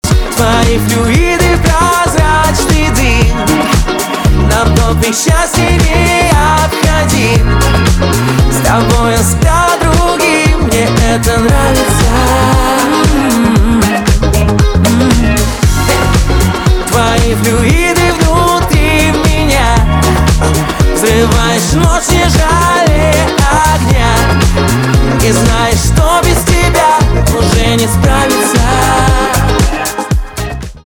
поп
романтические , чувственные , кайфовые , битовые , гитара